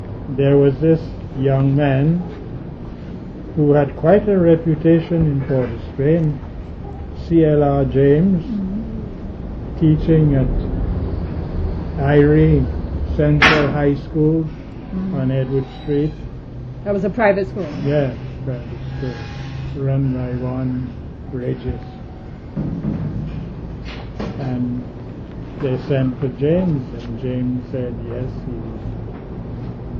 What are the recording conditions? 8 audio cassettes